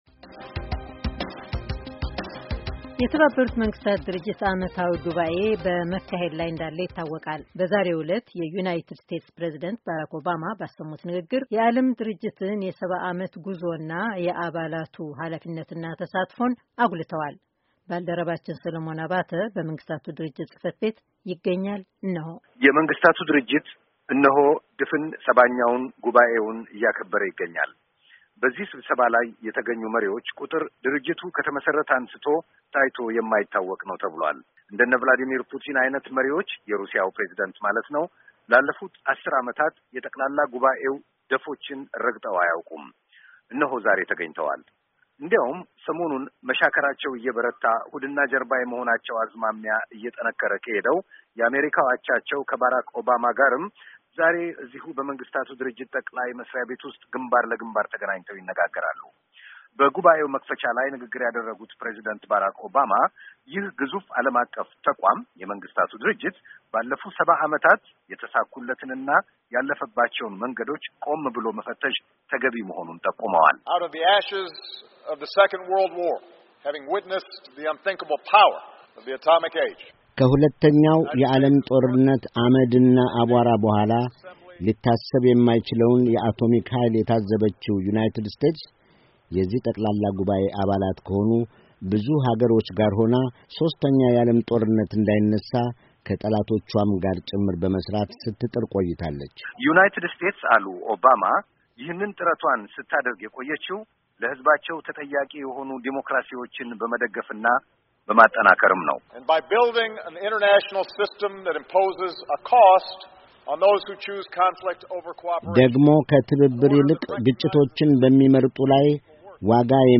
የ70ኛው መንገስታት ጠቅላላ ጉባኤ ከተባበሩት መንግስታቱ ድርጅት ጽ/ቤት 9'05"